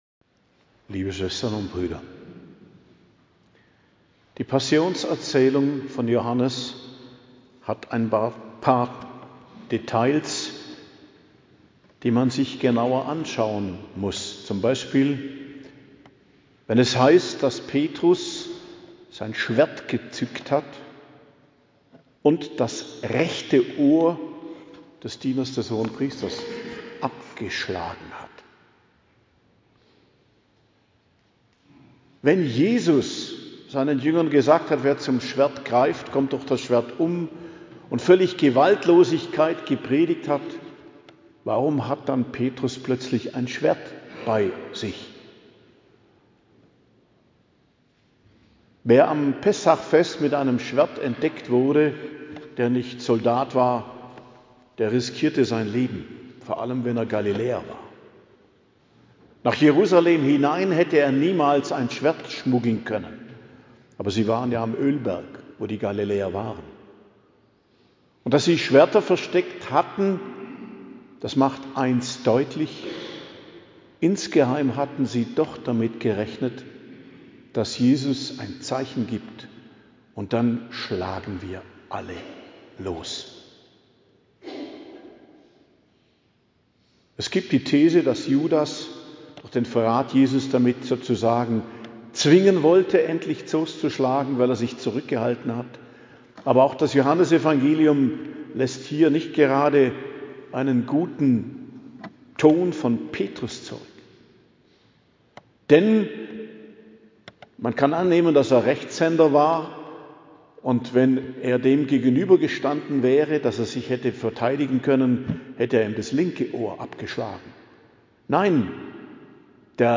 Predigt am Karfreitag, die Feier vom Leiden und Sterben Christi, 3.04.2026 ~ Geistliches Zentrum Kloster Heiligkreuztal Podcast